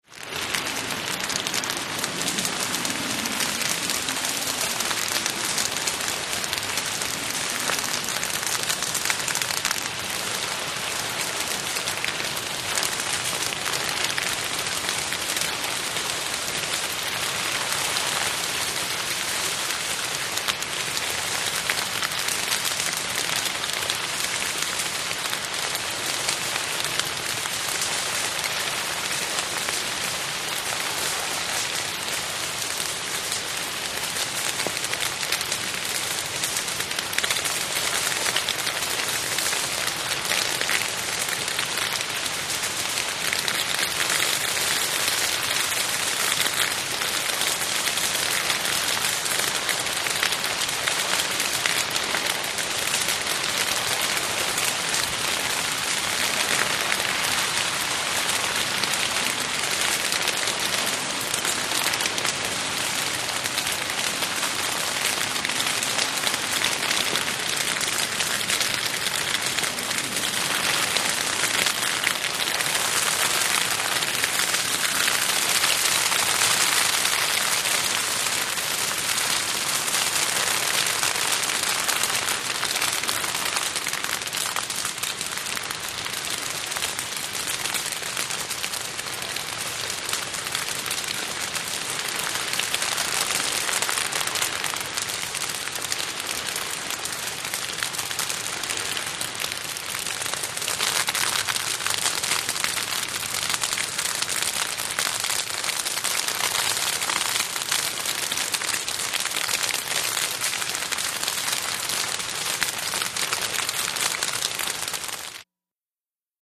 FIRE BRUSH FIRE EXT: Burning stubble in a wheat field, strength varies, distant crackles.. Fire Burn.